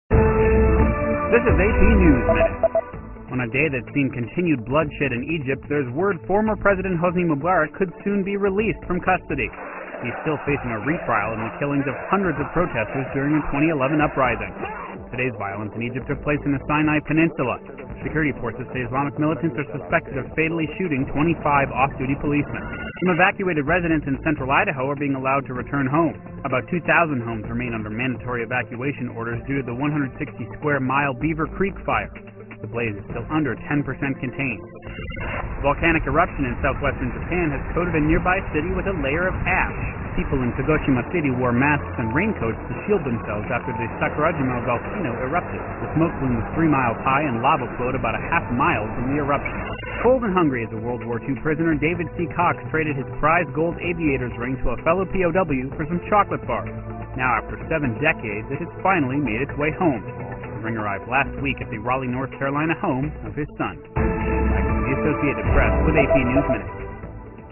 在线英语听力室美联社新闻一分钟 AP 2013-08-22的听力文件下载,美联社新闻一分钟2013,英语听力,英语新闻,英语MP3 由美联社编辑的一分钟国际电视新闻，报道每天发生的重大国际事件。电视新闻片长一分钟，一般包括五个小段，简明扼要，语言规范，便于大家快速了解世界大事。